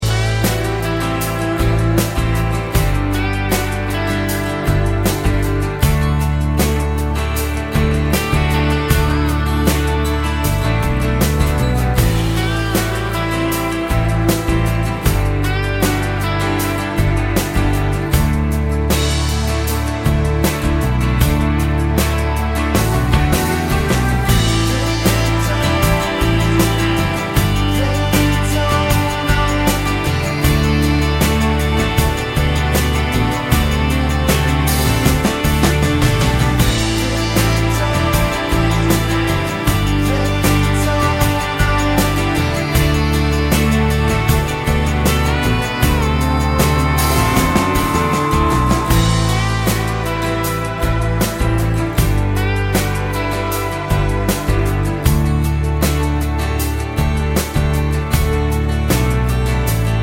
no Backing Vocals Indie / Alternative 5:47 Buy £1.50